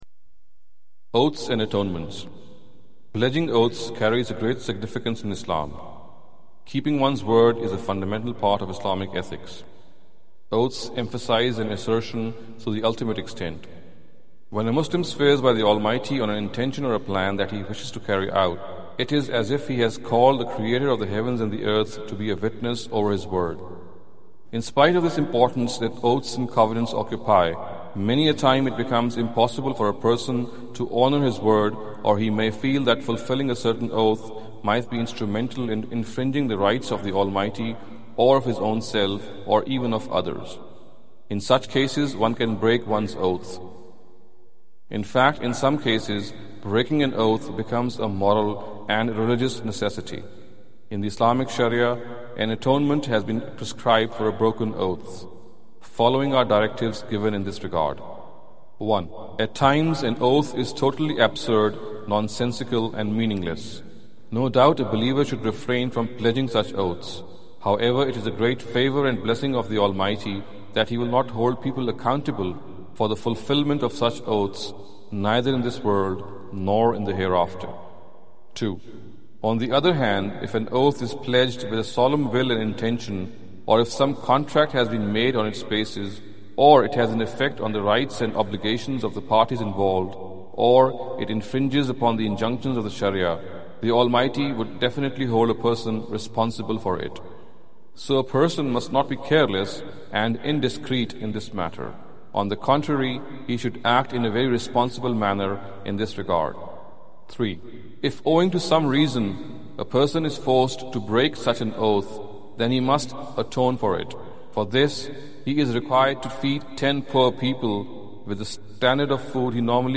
Category: Audio Books / Islam: A Concise Introduction /